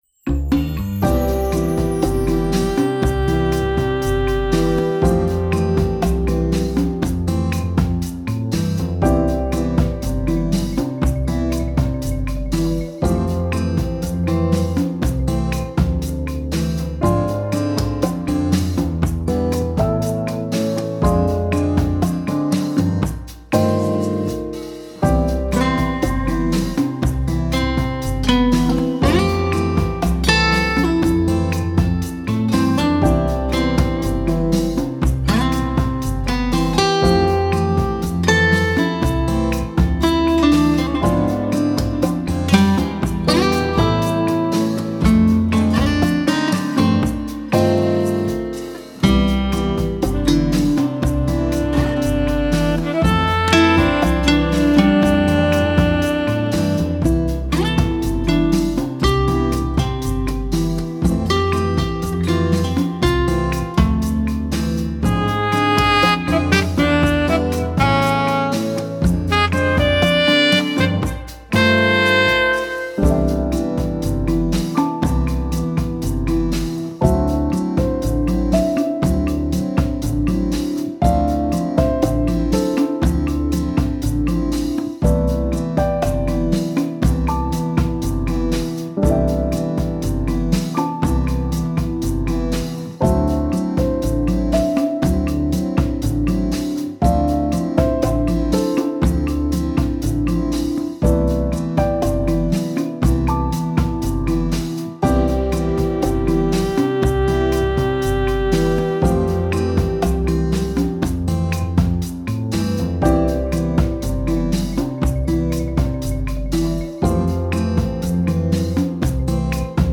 ゆったり穏やかなボサノバです。 目立ったメロディもそこまで無いため、ゲームや配信等のBGMとして最適です。